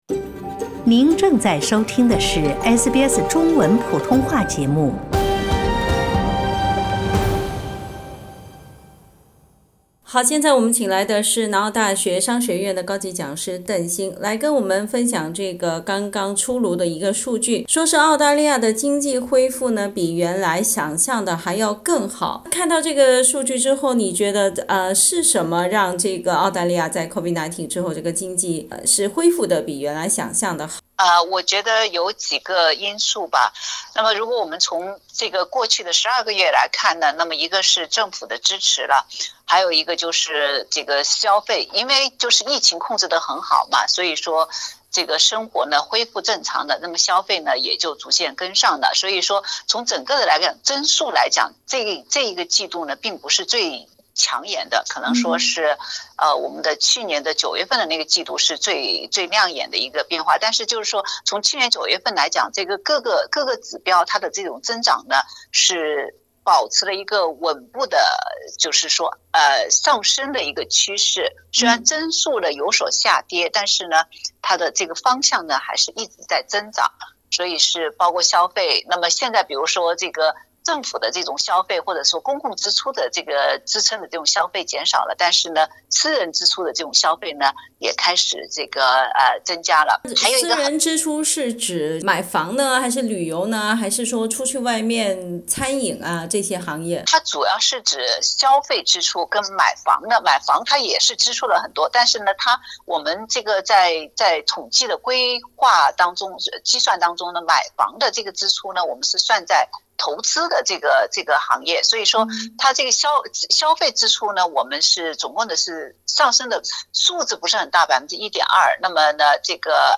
（本节目位嘉宾观点，请听采访） 澳大利亚人必须与他人保持至少1.5米的社交距离，请查看您所在州或领地的最新社交限制措施。